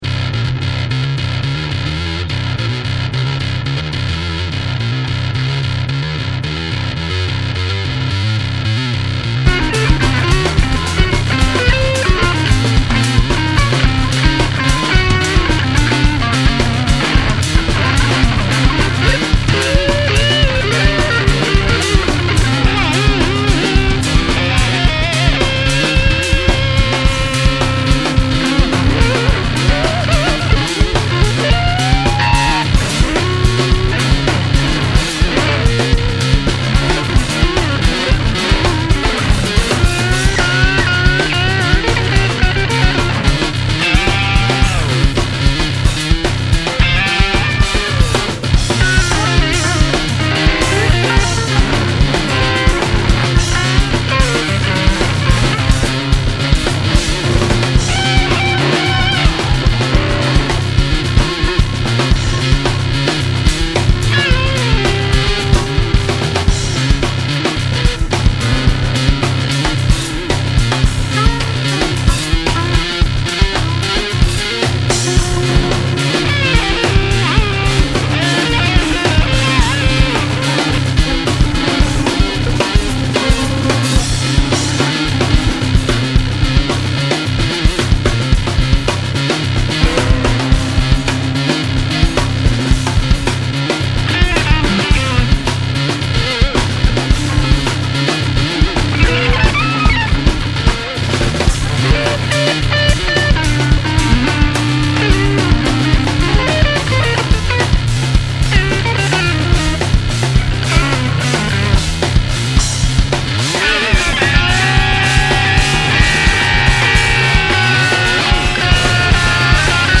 Prog/Jazz/World